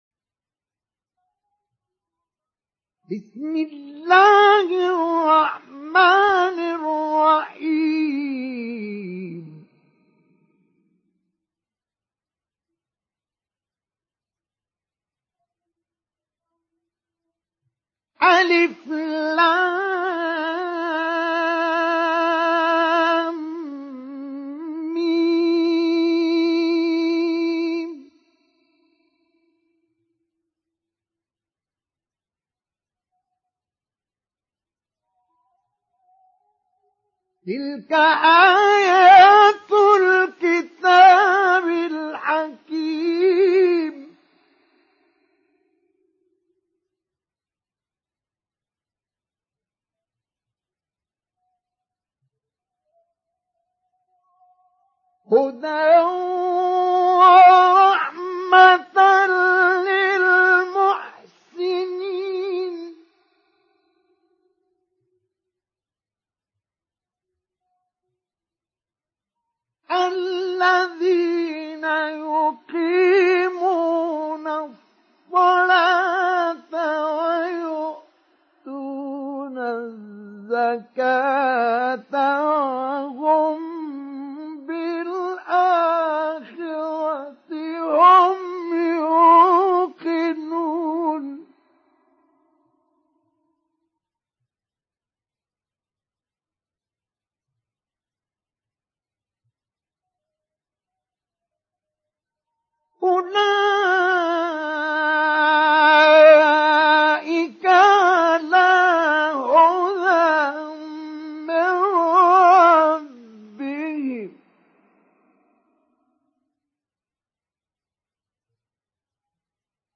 سُورَةُ لُقۡمَانَ بصوت الشيخ مصطفى اسماعيل